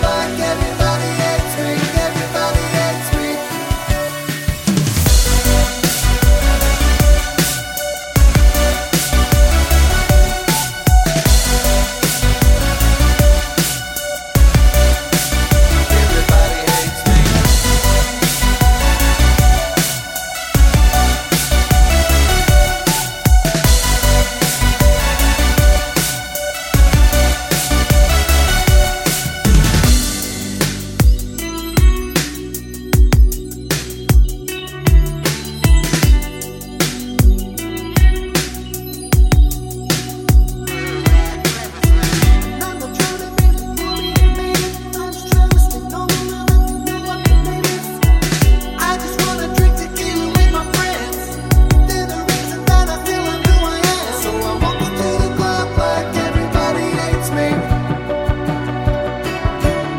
clean Pop (2010s) 3:43 Buy £1.50